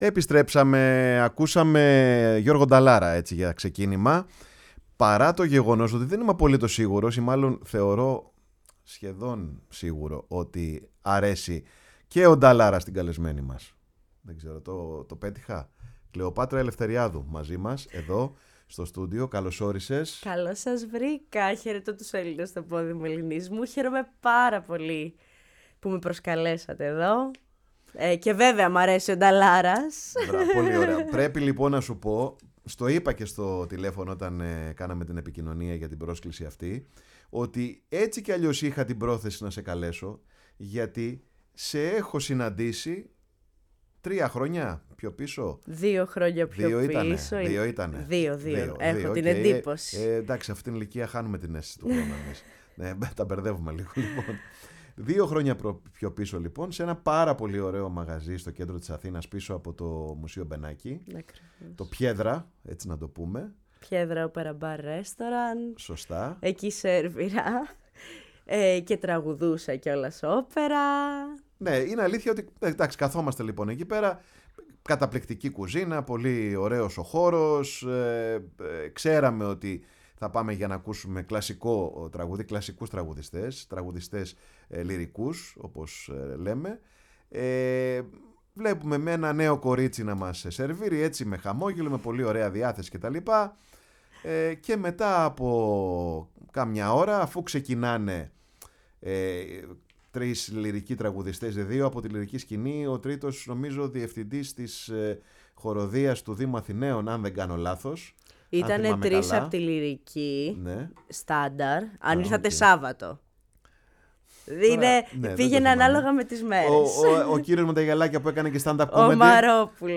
φιλοξένησε στο στούντιο η εκπομπή ”Πάρε τον χρόνο σου”